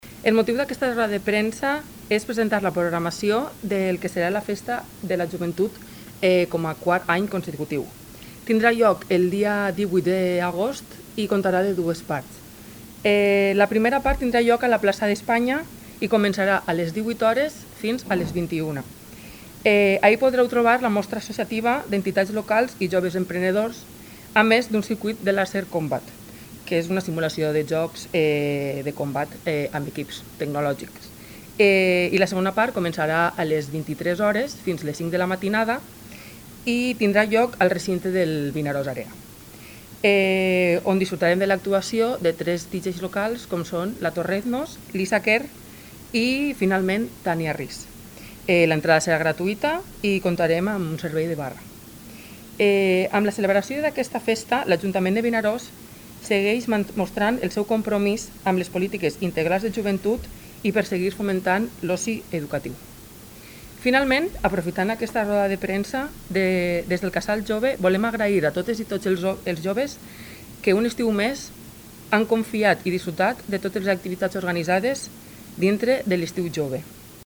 Regidor de Festes, Marc Albella
Regidora de Joventut, Lara Guadix